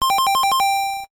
Sound effect of Extra Points in Mario Bros. (NES version).